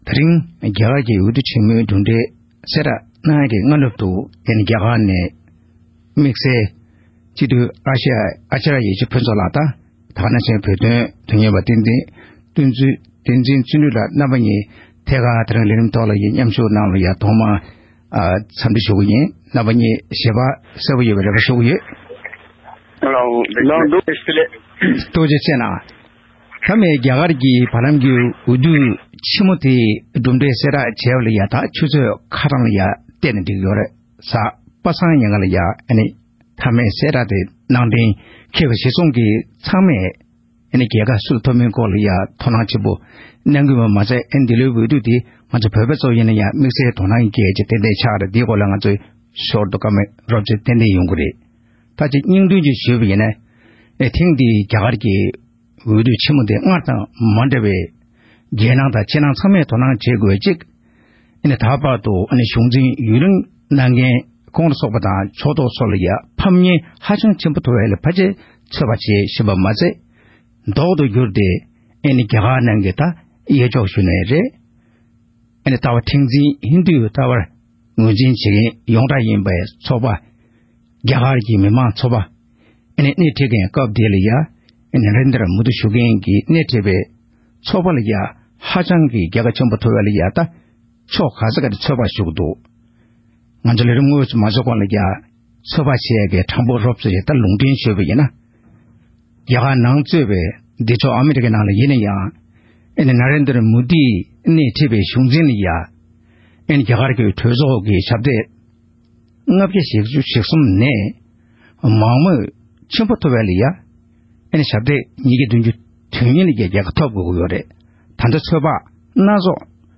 རྒྱ་གར་གྱི་འོས་བསྡུ་ཆེན་མོ་དང་འབྲེལ་ཆགས་གནས་ཚུལ་སྐོར་གླེང་མོལ་ཞུས་པའི་དམིགས་བསལ་ལས་རིམ།